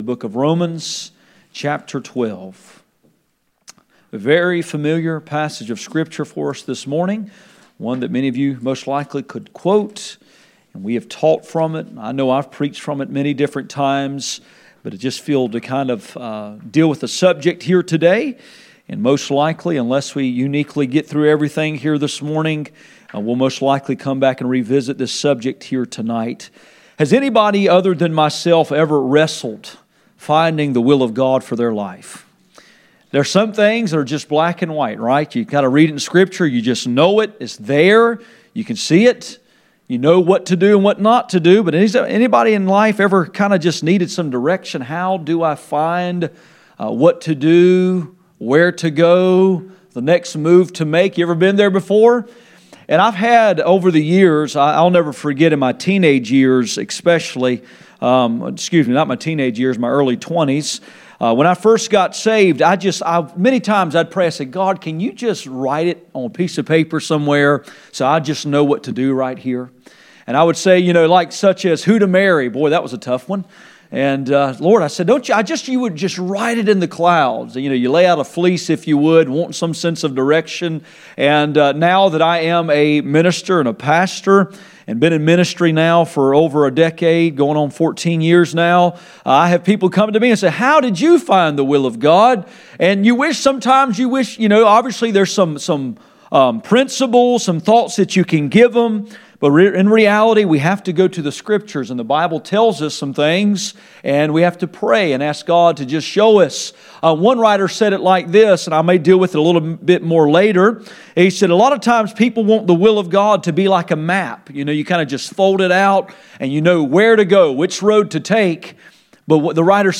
Passage: Romans 12:1-3 Service Type: Sunday Morning